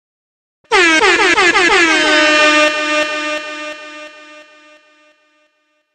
Air Horn